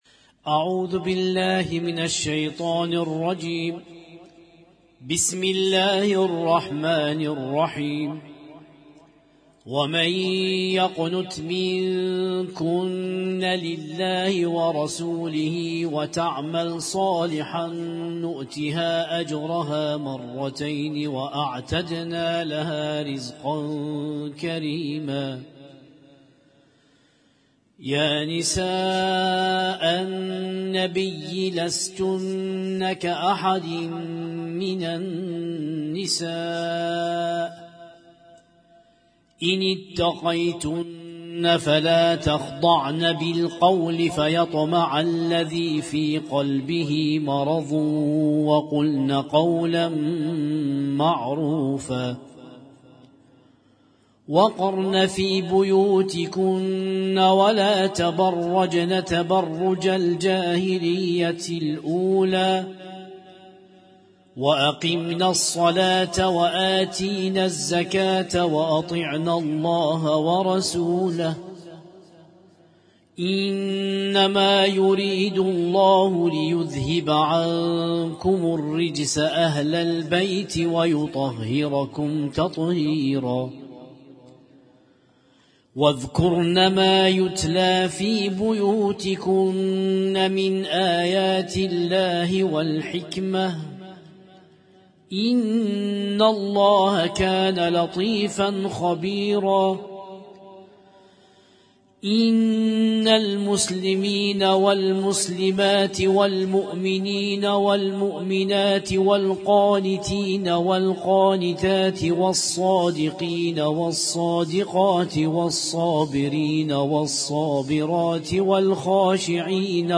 Husainyt Alnoor Rumaithiya Kuwait